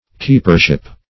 Keepership \Keep"er*ship\, n.